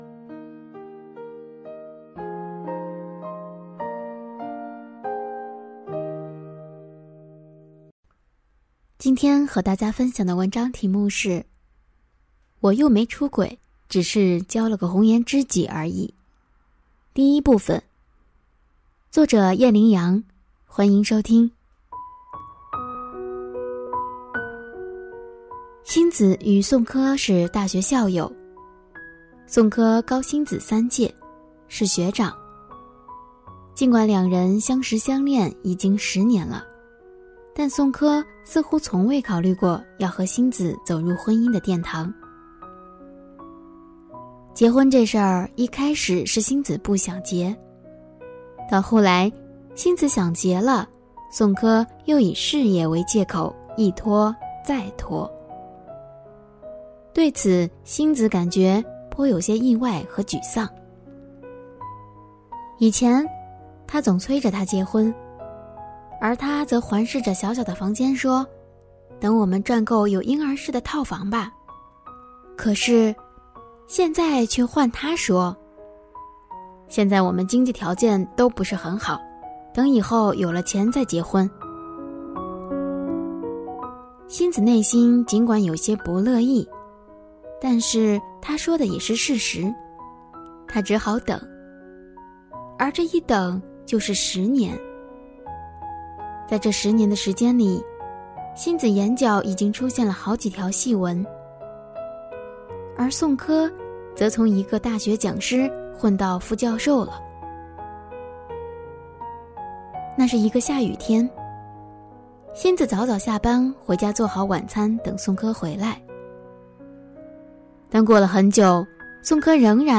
首页 > 有声书 > 婚姻家庭 > 单篇集锦 | 婚姻家庭 | 有声书 > 我又没出轨，只是交了个红颜知己而已（一）